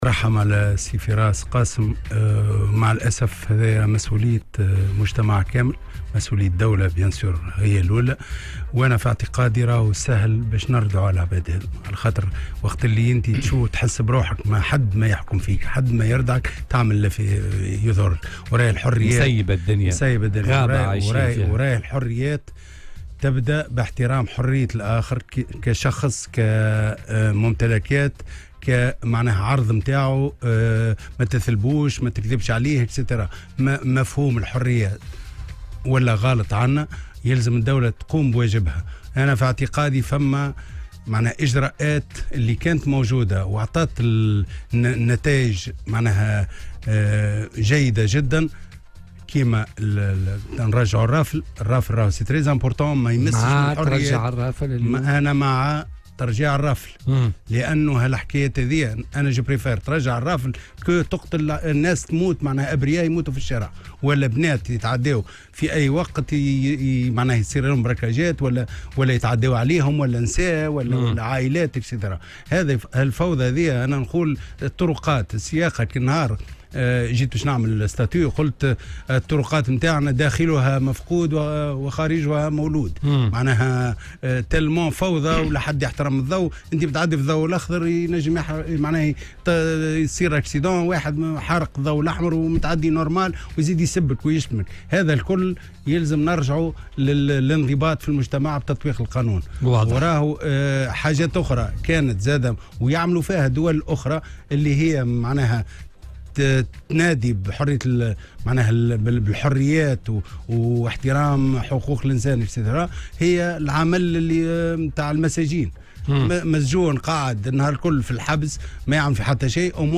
وقال في مداخلة له اليوم على "الجوهرة أف أم" إن "الرافل" لا يتعارض مع الحريات واحترام الحقوق".